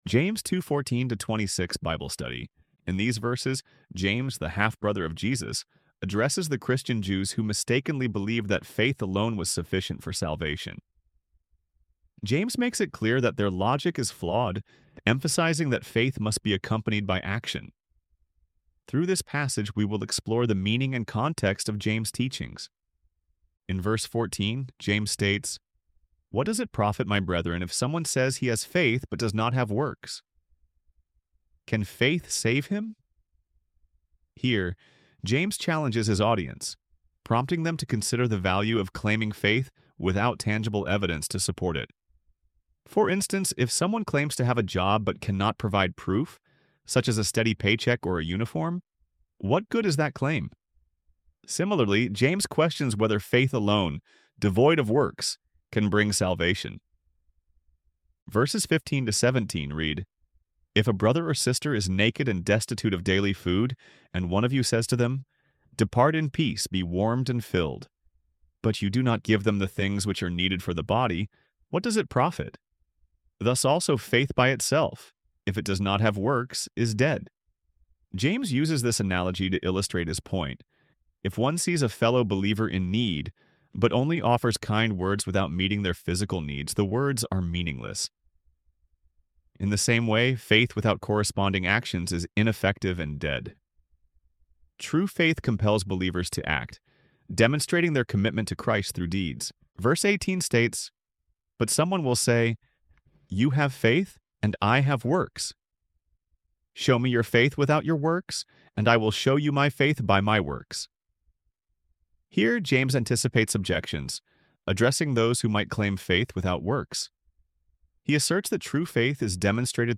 ElevenLabs_Bible_Study_on_Isaiah_55_1-7.-3.mp3